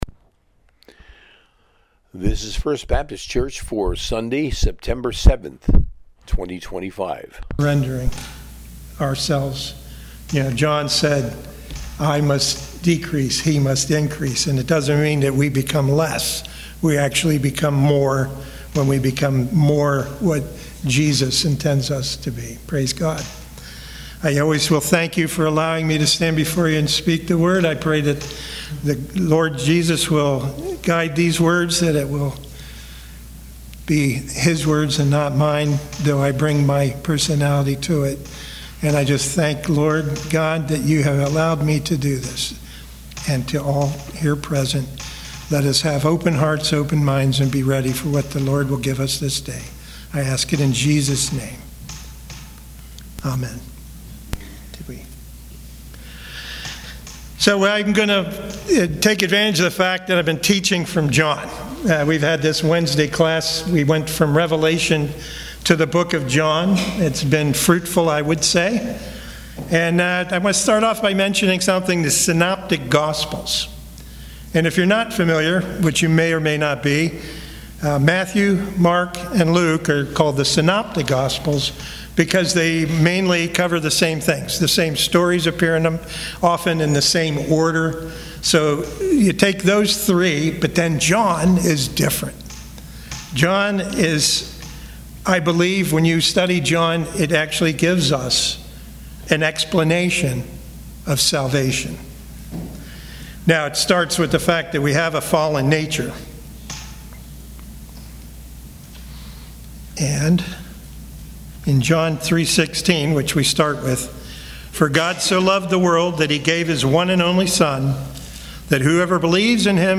Sunday Sermon